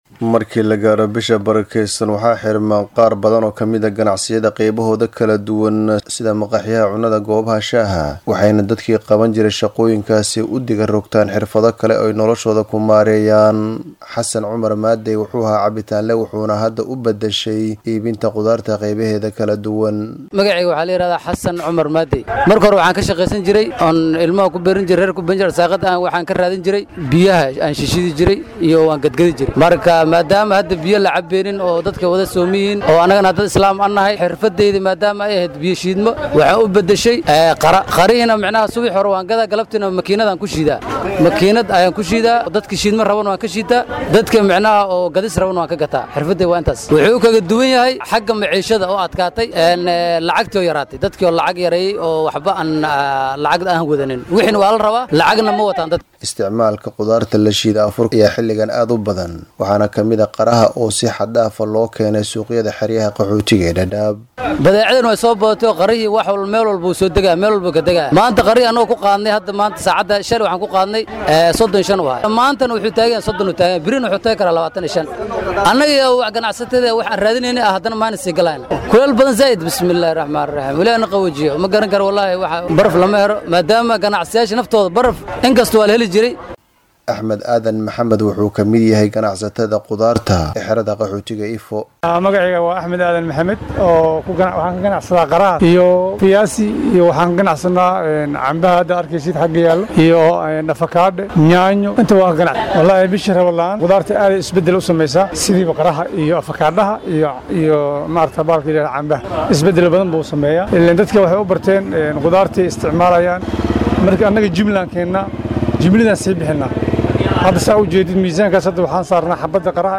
DHAGEYSO:Warbixin ku saabsan qaabka ay bishan dakhli ku helaan dadkii ka shaqeyn jiray maqaayadaha